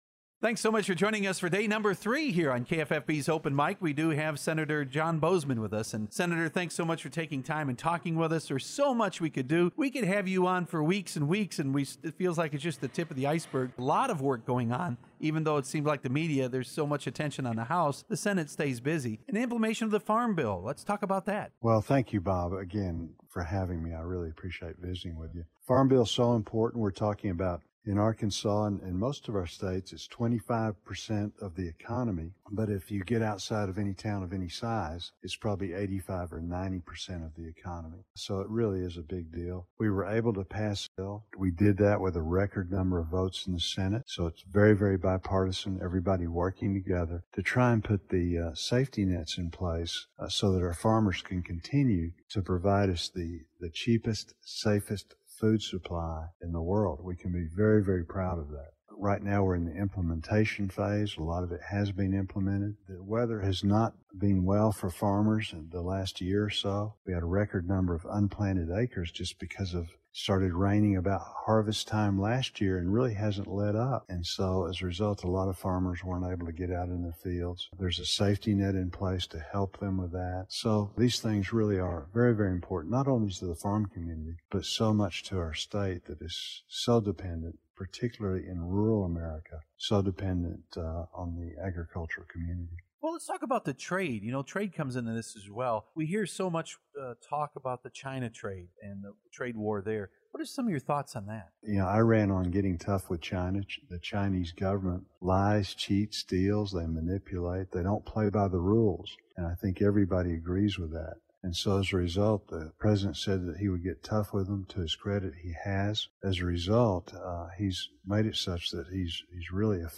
interview program